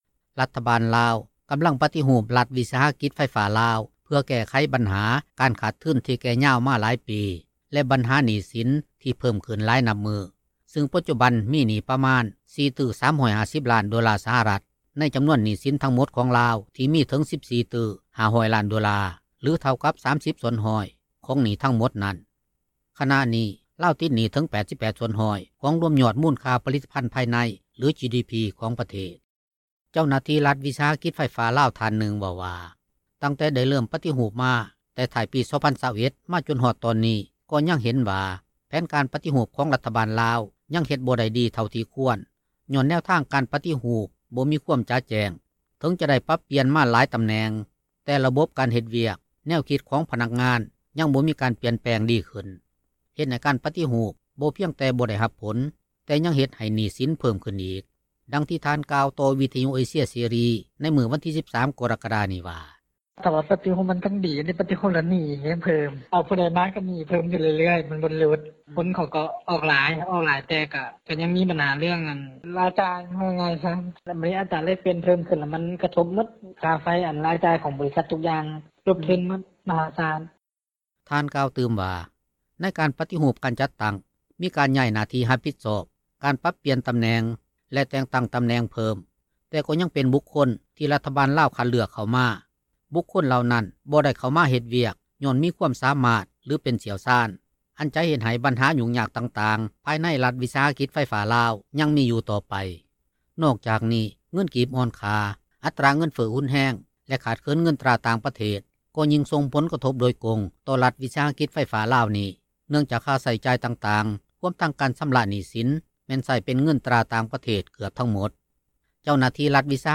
ນັກຂ່າວ ພົລເມືອງ
ດັ່ງປະຊາຊົນຜູ້ນຶ່ງ ໃນນະຄອນຫລວງວຽງຈັນ ກ່າວຕໍ່ວິທຍຸເອເຊັຽເສຣີໃນມື້ວັນທີ 13 ກໍຣະກະດານີ້ວ່າ: